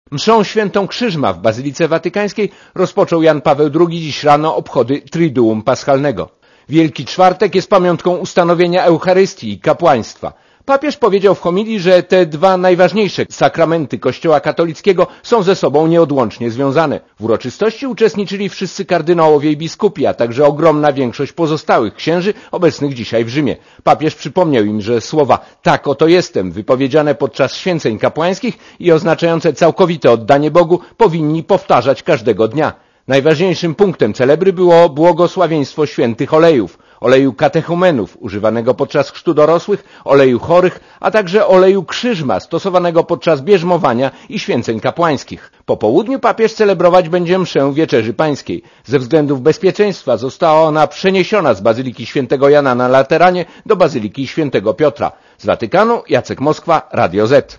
Posłuchaj relacji rzymskiego korespondenta Radia Zet (225 KB)